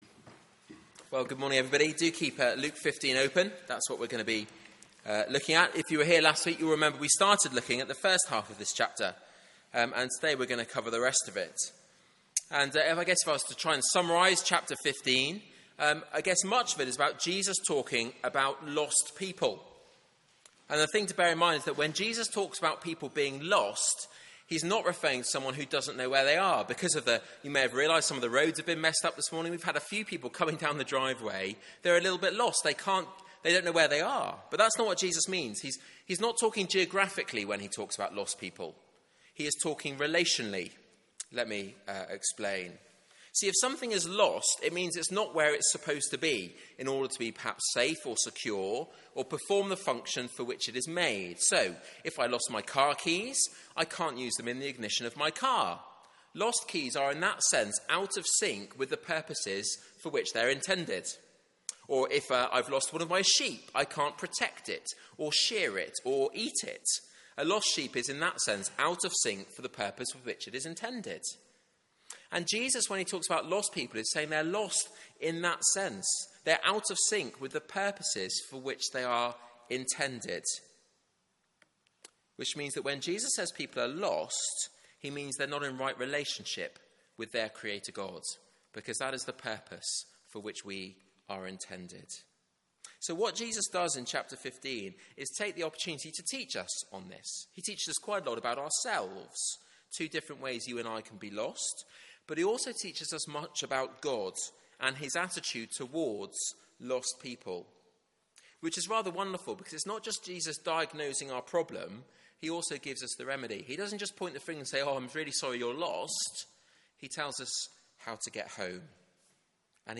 Media for 9:15am Service on Sun 12th Jul 2015 09:15 Speaker
Sermon (11:00) Sermon notes / slides Open Search the media library There are recordings here going back several years.